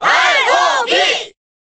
Category:Crowd cheers (SSBB) You cannot overwrite this file.
R.O.B._Cheer_Korean_SSBB.ogg.mp3